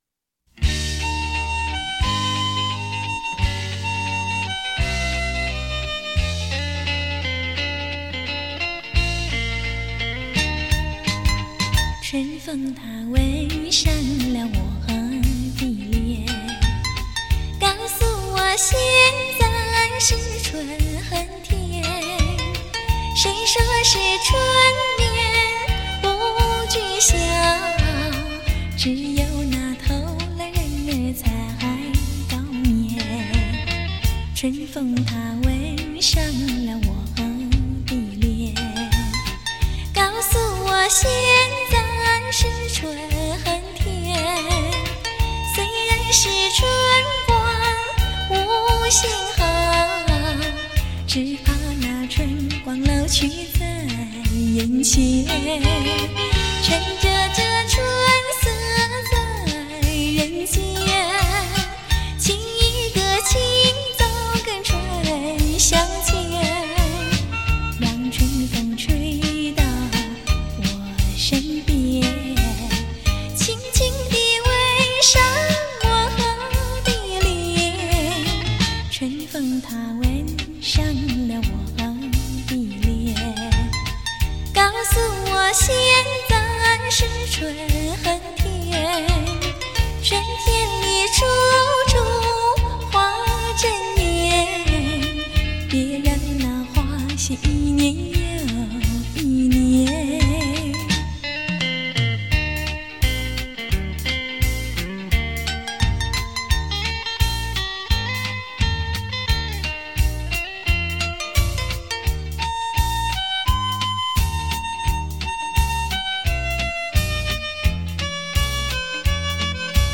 典雅温婉的歌声